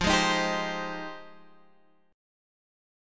Fm9 chord {x 8 6 8 8 8} chord